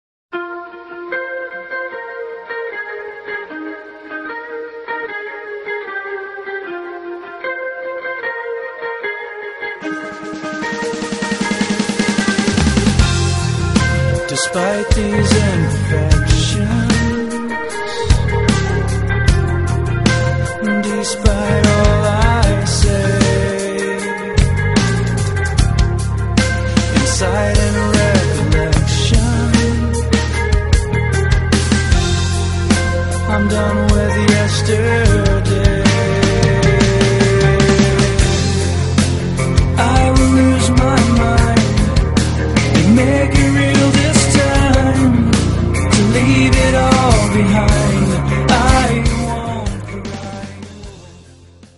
гитара
мужской вокал
грустные
мелодичные
Alternative Metal
nu metal
post-grunge